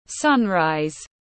Mặt trời mọc tiếng anh gọi là sunrise, phiên âm tiếng anh đọc là /ˈsʌn.raɪz/
Sunrise /ˈsʌn.raɪz/
Để đọc đúng mặt trời mọc trong tiếng anh rất đơn giản, các bạn chỉ cần nghe phát âm chuẩn của từ sunrise rồi nói theo là đọc được ngay.